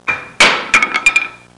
Falling Wood Sound Effect
Download a high-quality falling wood sound effect.
falling-wood-1.mp3